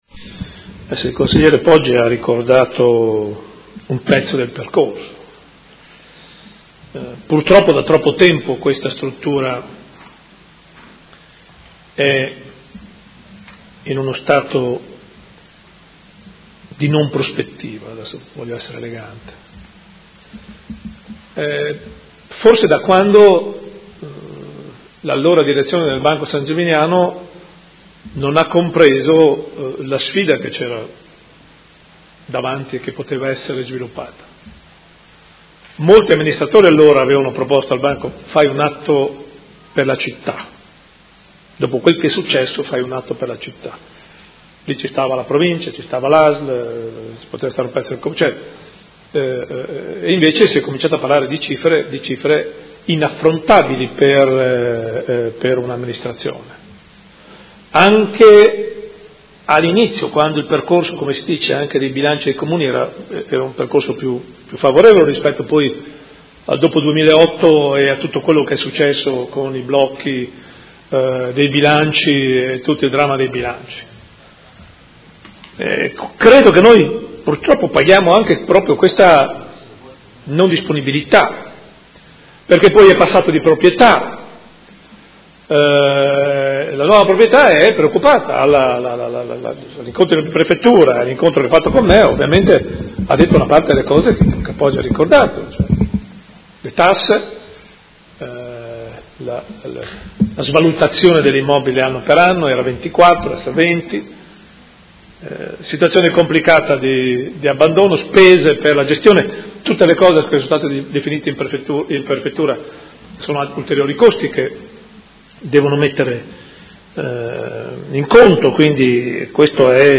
Seduta del 28/04/2016. Interrogazione della Consigliera Pacchioni (P.D.) avente per oggetto: Centro Direzionale Manfredini.